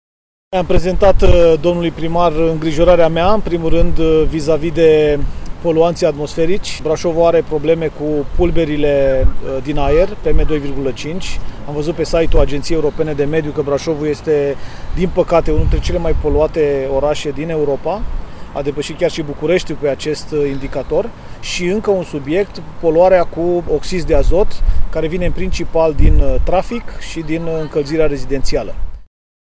În ciuda faptului că este un oraș de munte, care ar trebui, teoretic, să aibă un aer curat, Brașovul este mai poluat chiar și decât Bucureștiul, după cum a obsevat secretarul de stat din Ministerul Mediului, Sorin Ionuț Banciu care a ținut să discute această problemă gravă cu autoritățile locale.